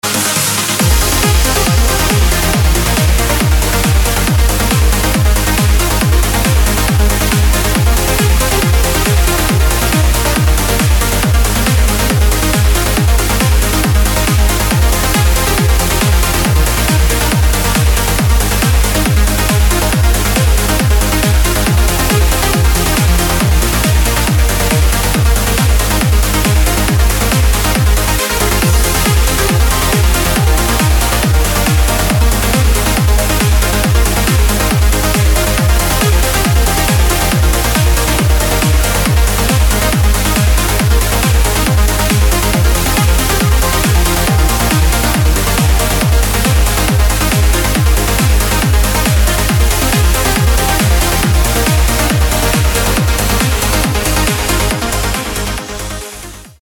• Качество: 256, Stereo
красивые
dance
Electronic
без слов
club
Trance
быстрые
Uplifting trance